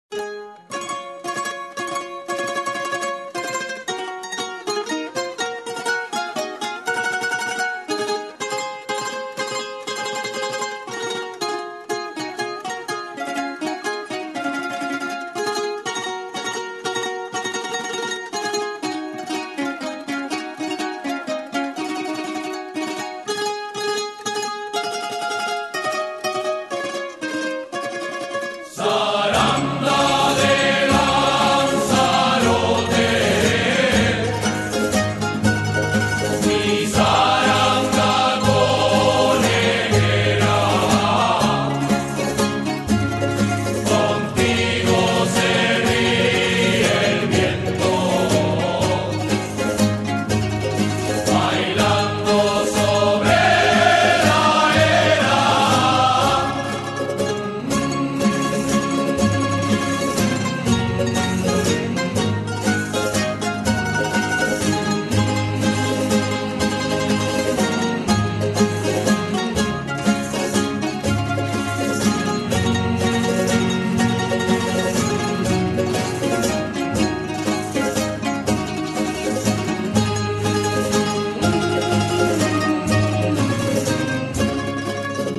violín y acordeón